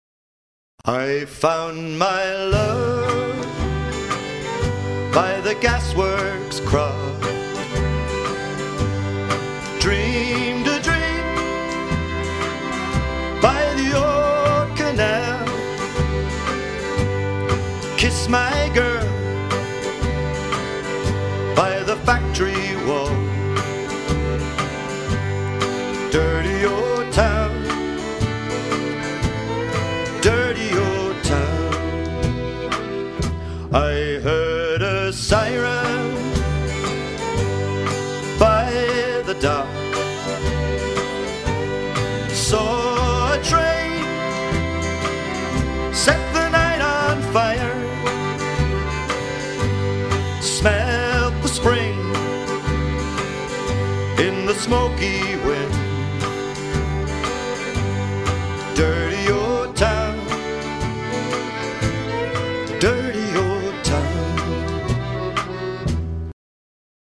IRISH AND CEILIDH